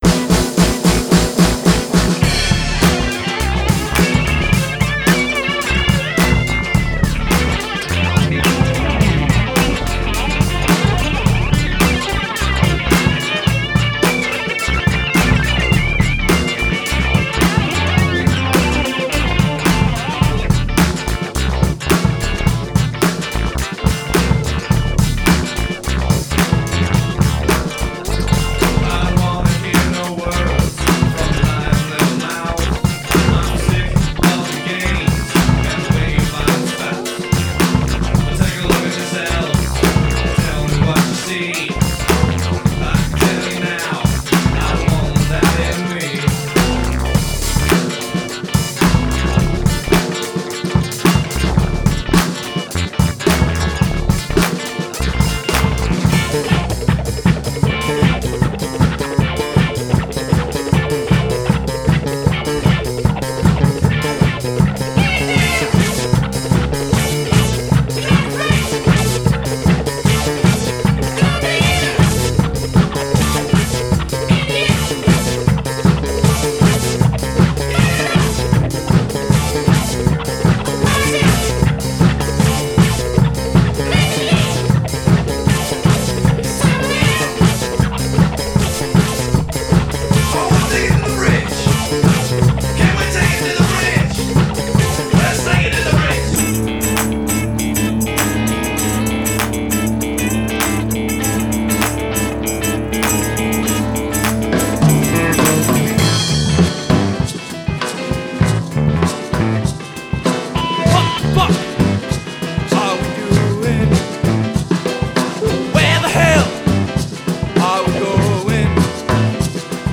Guitar, Bass, Simple Keys, Vocals
Vocals, Trombone, Nose Flute and other annoying instruments
Guitar, Bass, Keys, Clarinet, Tenor Sax,
Harmonica, Lap Steel, Mandolin, Violin, Tonnette, Recorder,
The really cool keyboard parts
Drums
Alto, Tenor and Soprano Sax
Written, Recorded and Mixed at the Rectory